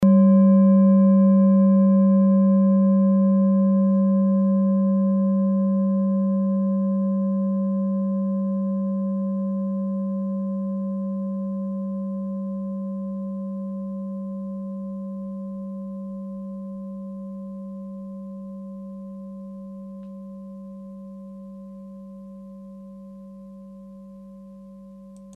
Klangschale Orissa Nr.20
Klangschale-Gewicht: 1130g
Klangschale-Durchmesser: 19,4cm
Sie ist neu und wurde gezielt nach altem 7-Metalle-Rezept in Handarbeit gezogen und gehämmert.
Tageston:
klangschale-orissa-20.mp3